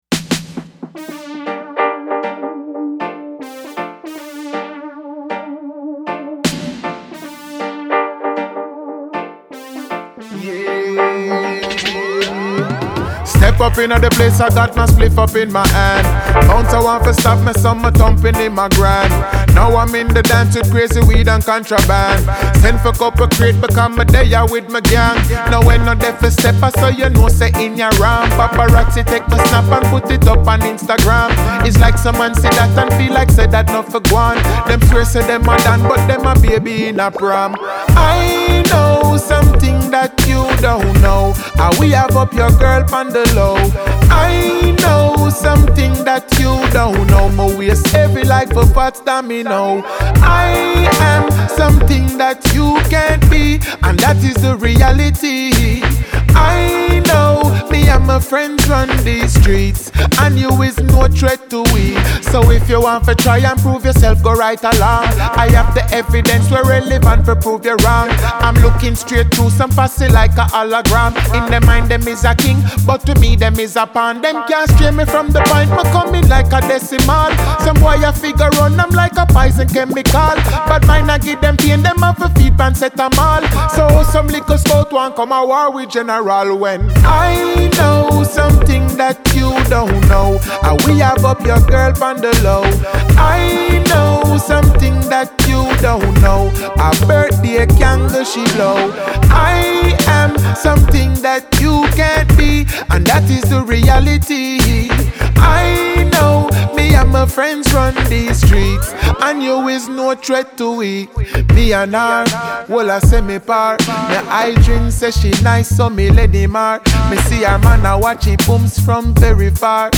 dancehall
reggae